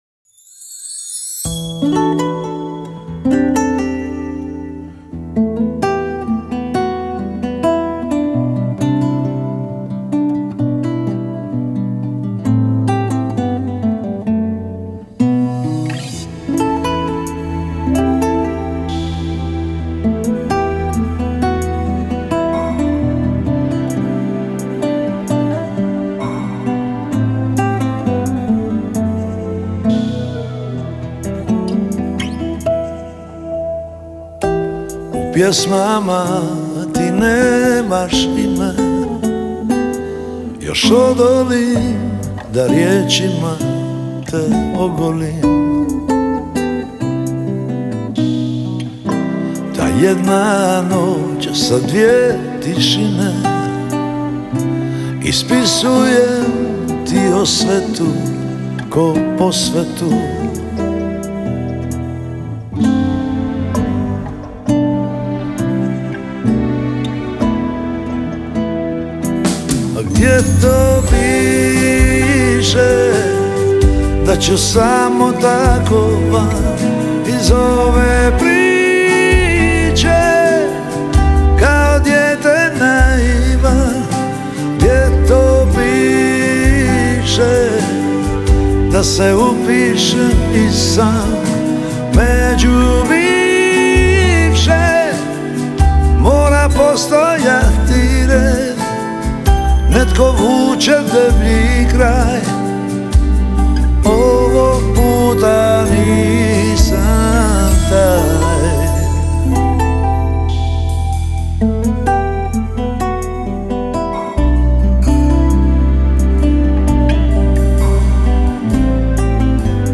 gitare, klavijature, udaraljke
Bubnjeve